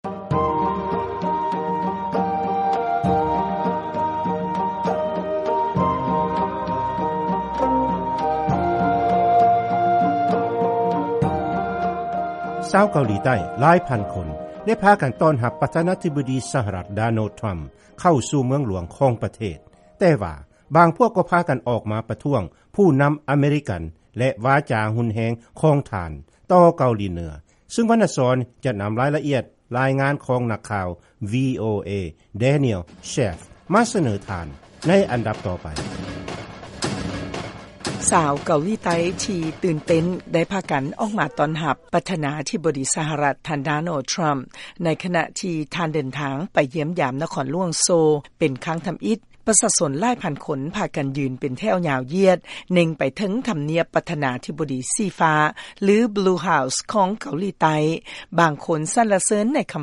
ລາຍງານກ່ຽວກັບປະຊາຊົນຊາວເກົາຫຼີໃຕ້ໃຫ້ການຕ້ອນຮັບປະທານາທິບໍດີທຣຳ